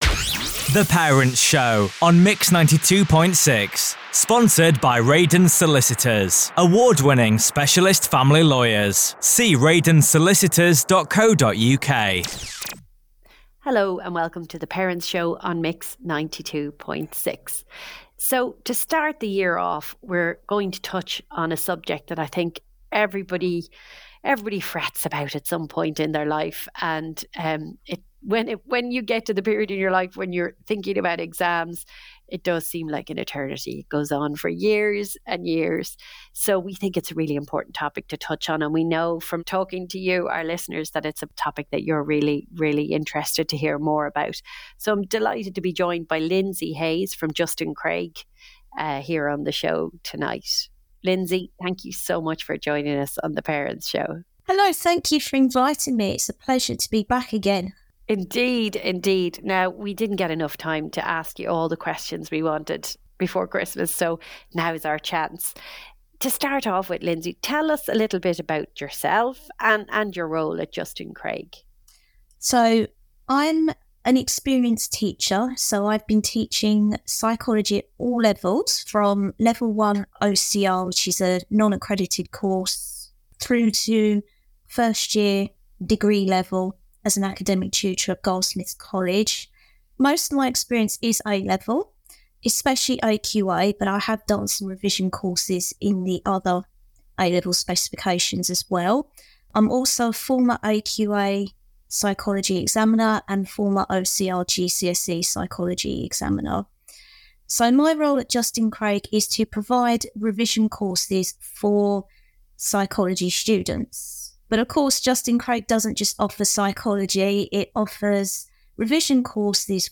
In an expanded interview that takes up the whole show this week